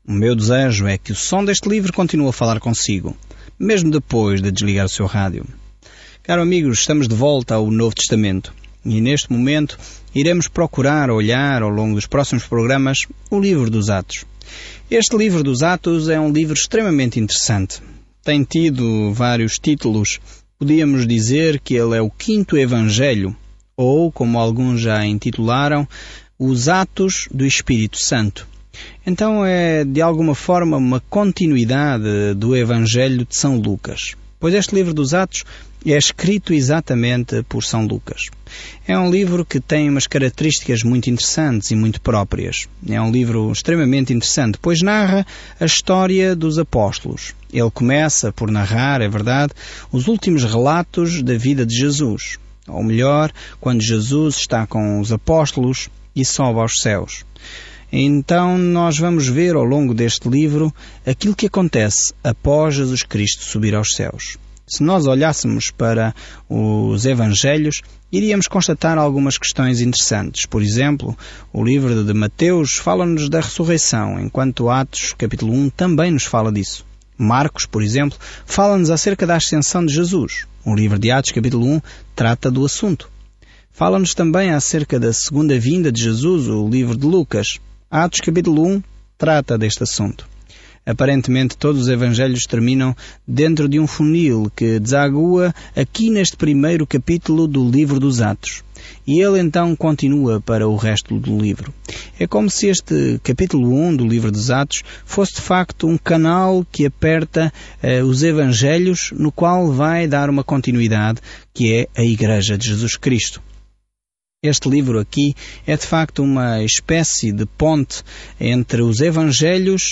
Escritura ATOS DOS APÓSTOLOS 1:1-3 Iniciar este Plano Dia 2 Sobre este plano A obra de Jesus iniciada nos Evangelhos continua agora através do seu Espírito, à medida que a igreja é plantada e cresce em todo o mundo. Viaje diariamente por Atos enquanto ouve o estudo em áudio e lê versículos selecionados da palavra de Deus.